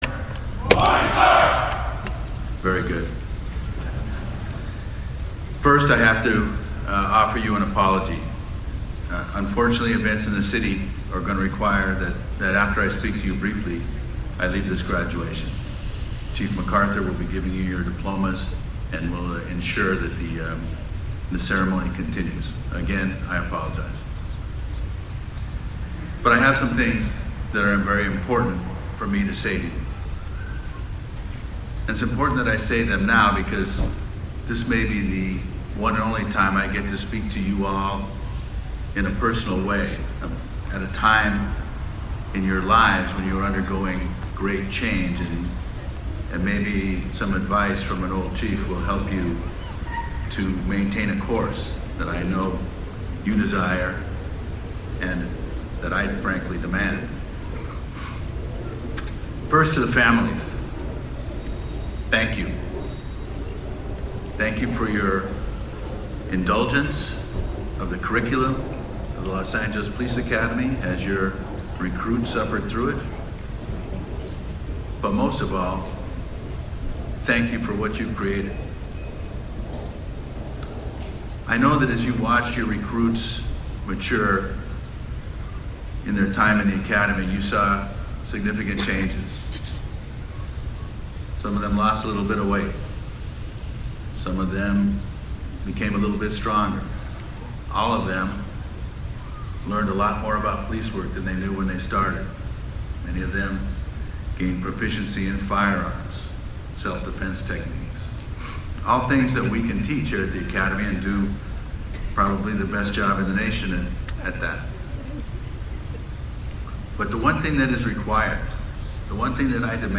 November 1, 2013- The newest Los Angeles Police Academy graduates proudly stood at attention on the athletic fields of the as they received the diplomas. The newest members of the Department were addressed by Chief Charlie Beck, he reminded the recruits they now carry a great responsibility, not a gift, but an authority which has been granted by the people of Los Angeles.
The Forty-one individuals underwent six months of intense training which culminated with today’s ceremony in front of Department command staff, Los Angeles Mayor Eric Garcetti, dignitaries and their family and friends.